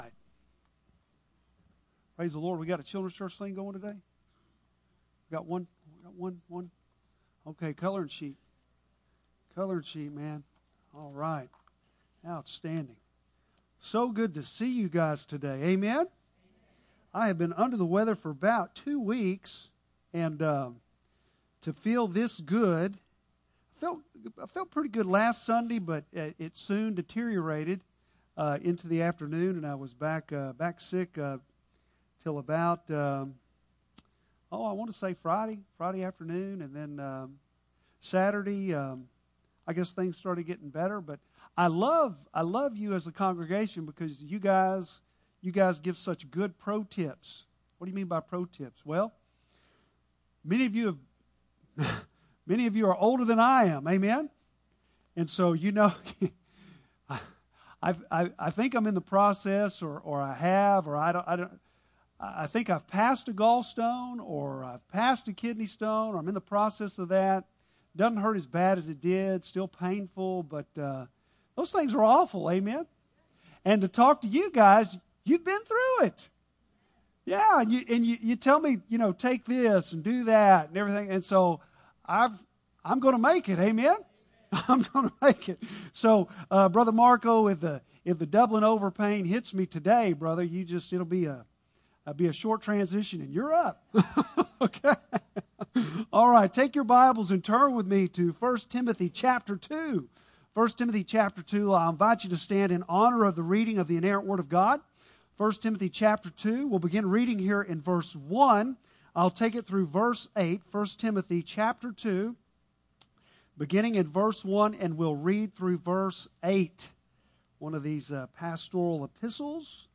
Bible Text: I Timothy 2:1-8 | Preacher